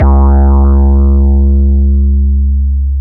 808 Kick 14_DN.wav